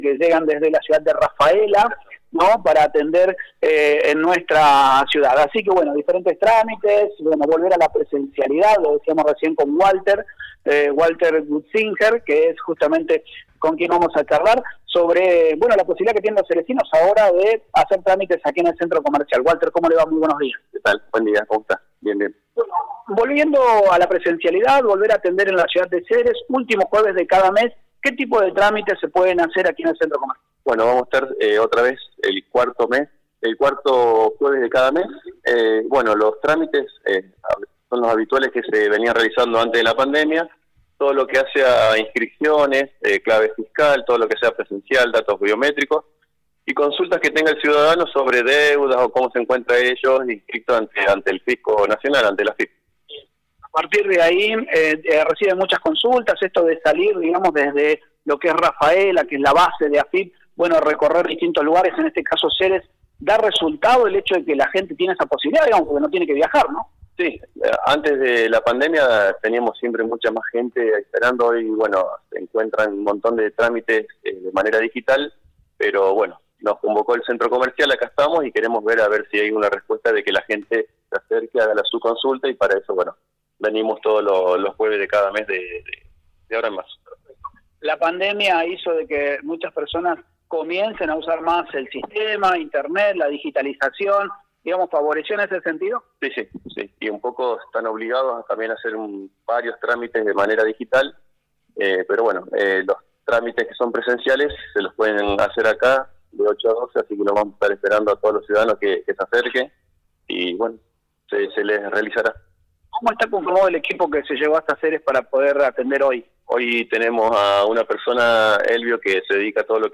En la Radio realizamos un móvil desde el lugar